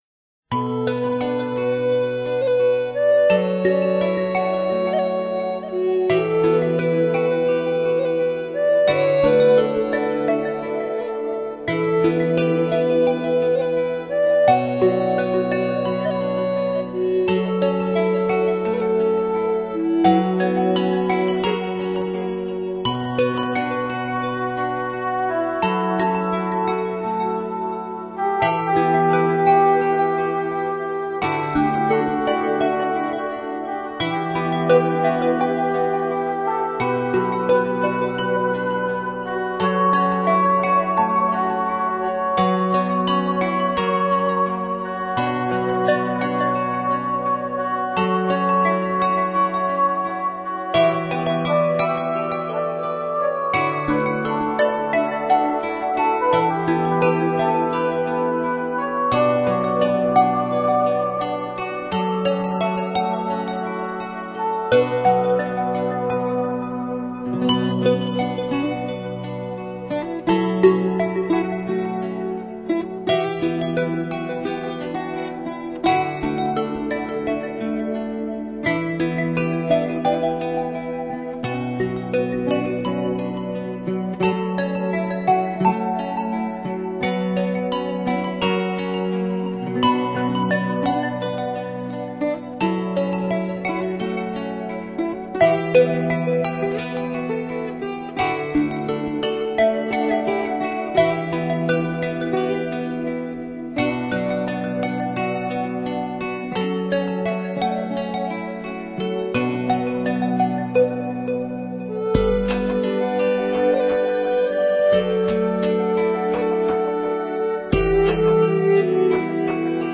突出Phrase Sampling以及纯乐器的音色、是前面没有出现过的电子音乐。